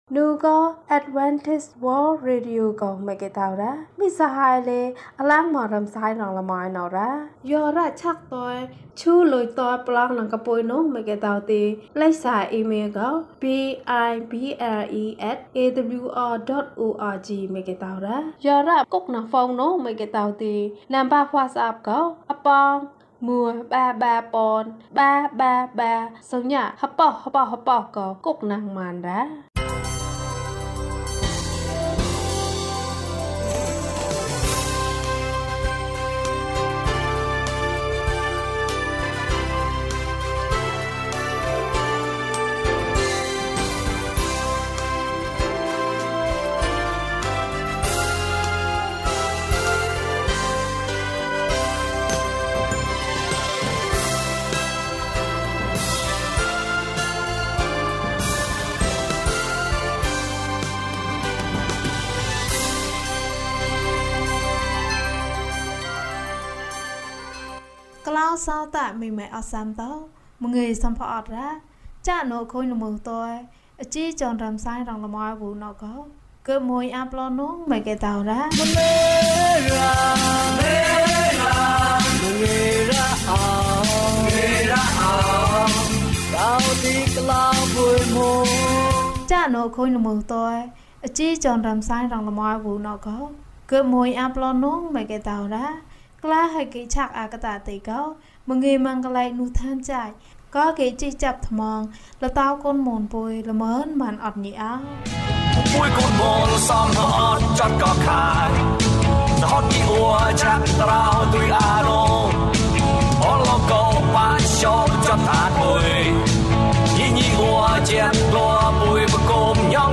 အသက်ရှင်သောဘဝ။ ကျန်းမာခြင်းအကြောင်းအရာ။ ဓမ္မသီချင်း။ တရားဒေသနာ။